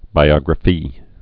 (bī-ŏgrə-fē)